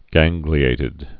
(găngglē-ātĭd) also gan·gli·ate (-ĭt, -āt)